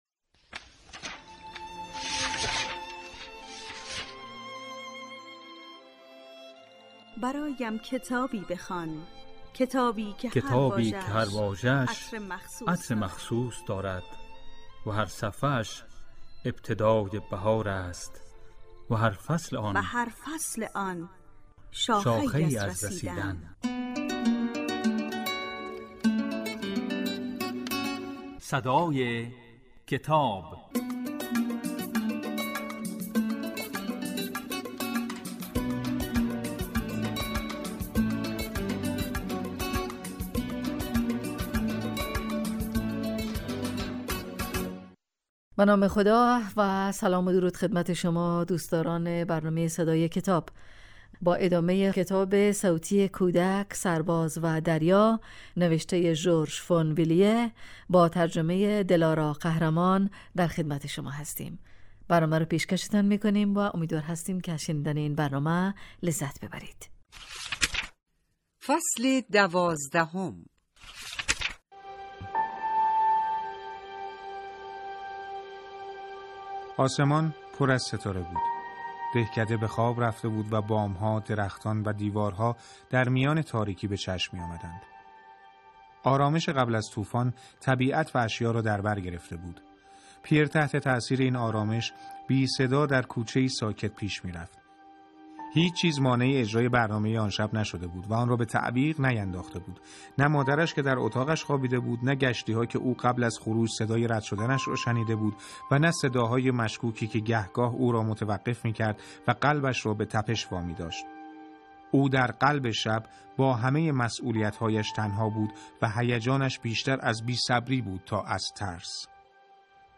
این برنامه کتاب صوتی است و در روزهای یکشنبه، سه شنبه و پنج شنبه در بخش صبحگاهی پخش و در بخش نیمروزی بازپخش می شود.